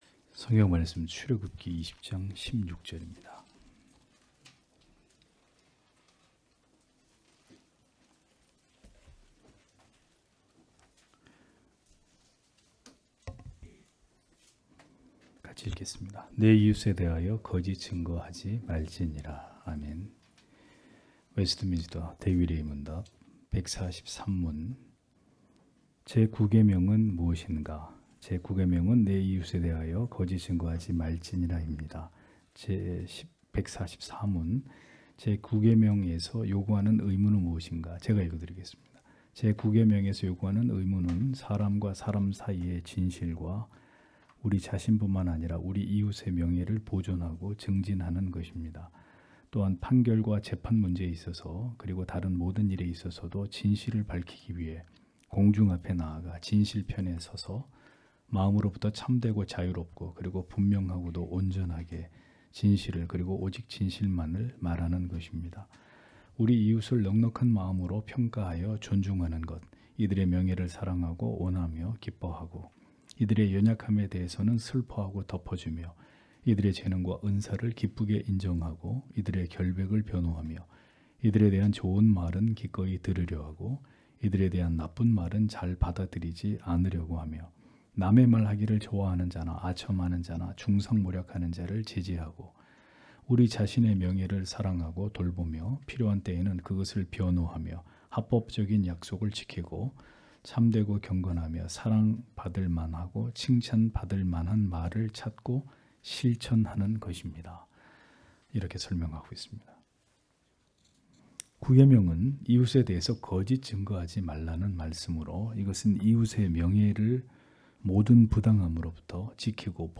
주일오후예배 - [웨스트민스터 대요리문답 해설 143-144] 143문) 제 9계명은 네 이웃에 대하여 거짓 증거하지 말지니라 입니다.
* 설교 파일을 다운 받으시려면 아래 설교 제목을 클릭해서 다운 받으시면 됩니다.